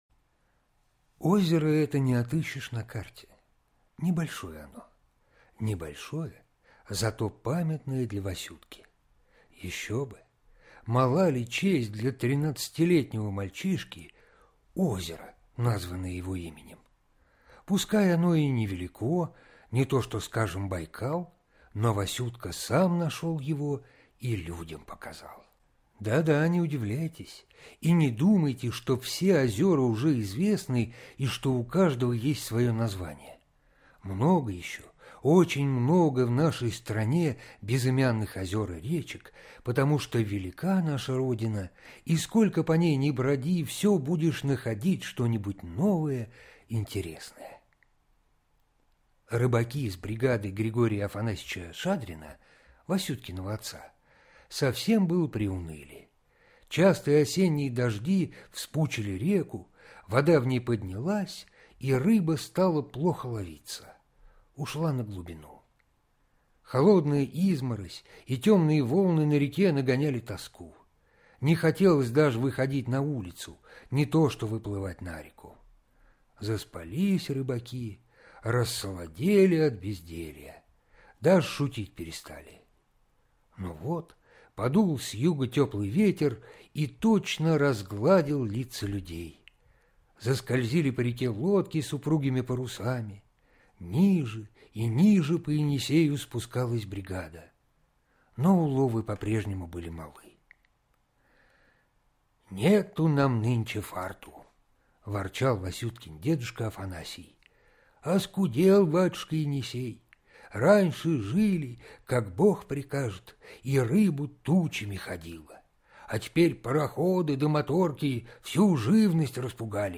Васюткино озеро - аудио рассказ Астафьева В.П. Рассказ про тринадцатилетнего Васютку, который пошел в тайгу за орешками и заблудился.